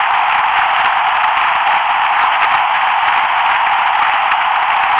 Noise
Noise sounds like whistling wind, or crinkling tin foil. Here are a couple of examples of how noise signals can sound.